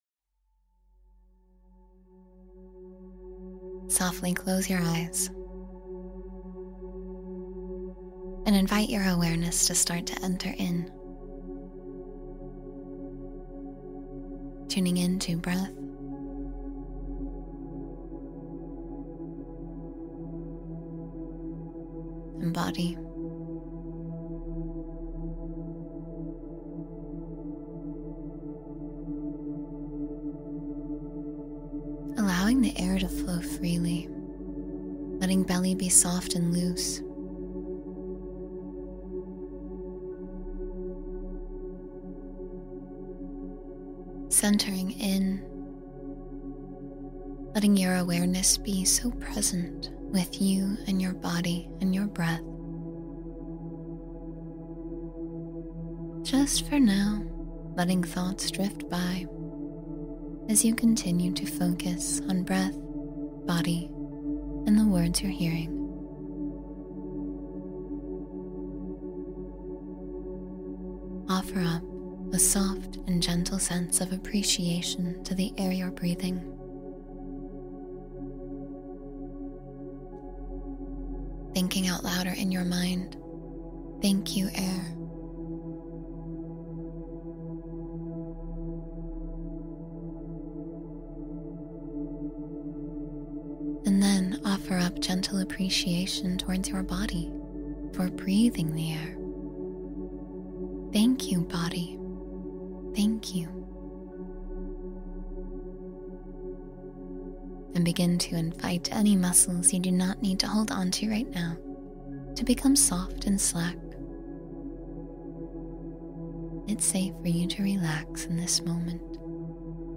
Quiet the Mind and Discover Inner Peace — Guided Meditation for Mental Calm